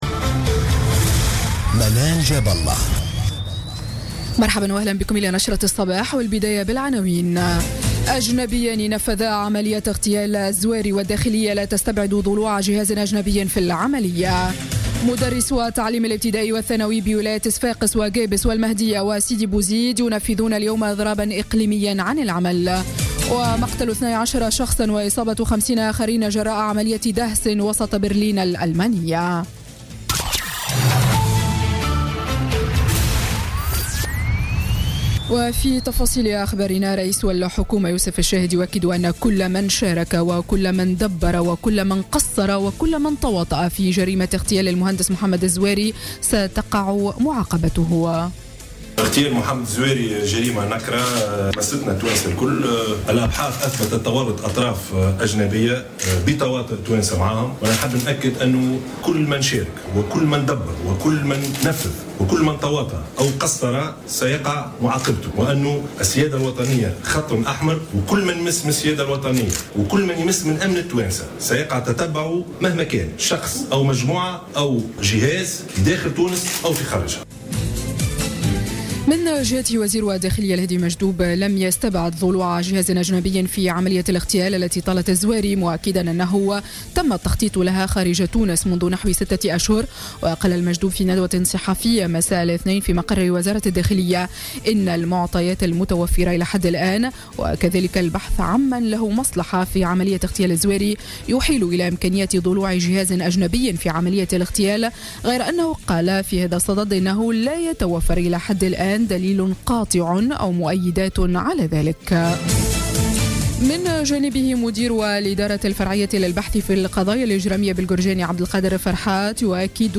نشرة أخبار السابعة صباحا ليوم الثلاثاء 20 ديسمبر 2016